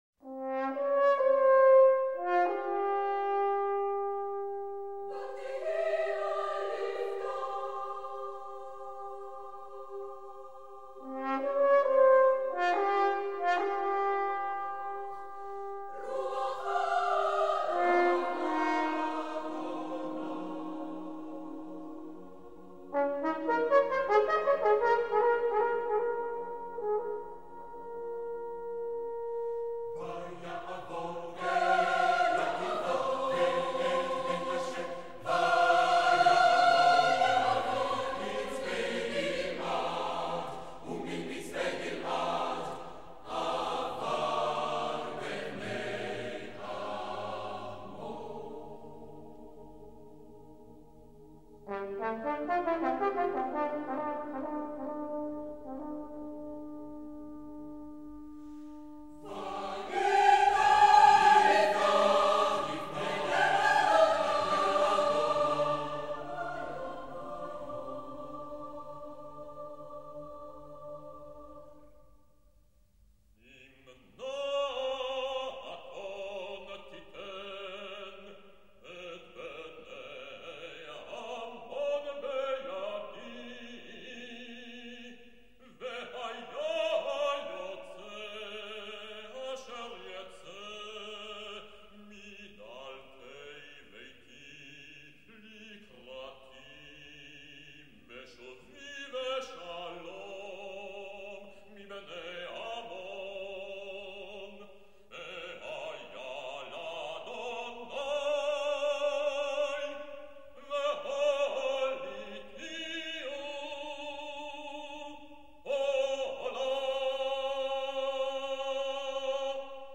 French horn
soprano soloist
baritone soloist